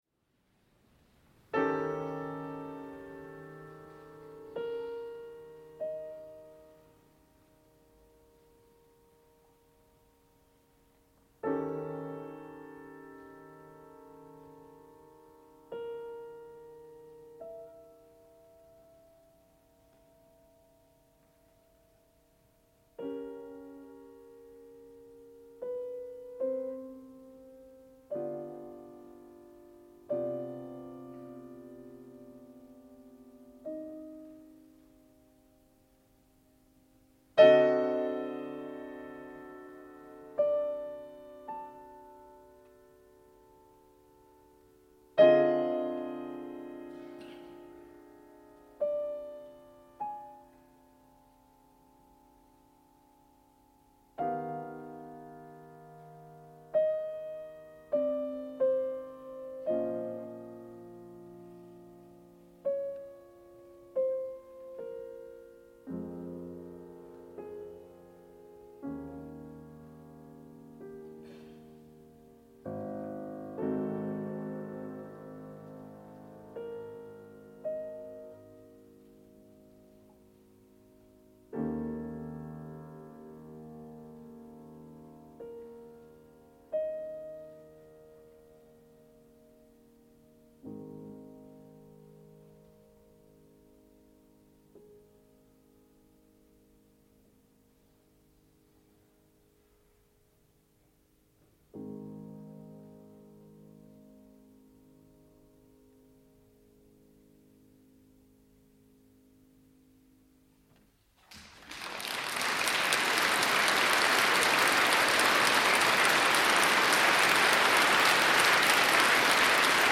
Крупнейший национальный правообладатель – фирма «Мелодия» выпустила ограниченным тиражом коллекционное собрание записей пианиста Святослава Рихтера к 100-летию со дня его рождения.
Бокс, выполненный в виде рояля, включает в себя 50 дисков с концертными и домашними записями великого пианиста.